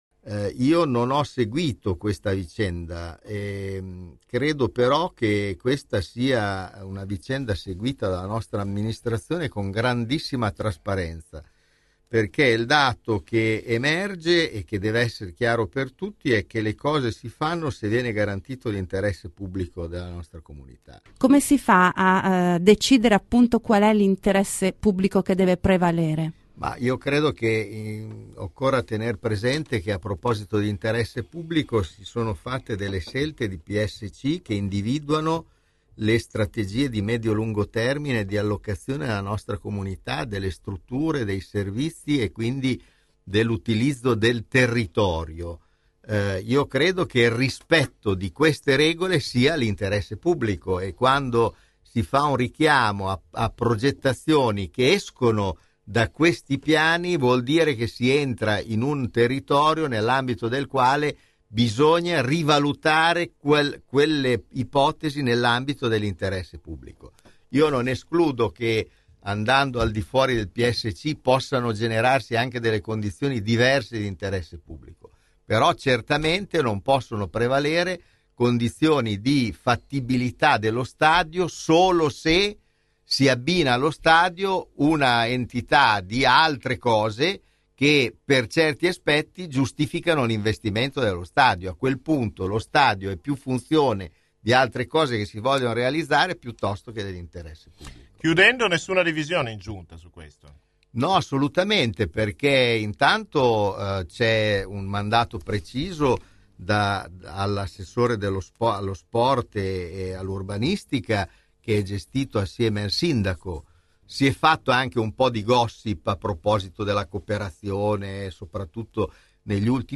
In apertura di intervista Luciano Sita ha detto di non aver ambizioni politiche e che questo sarà il suo unico mandato amministrativo.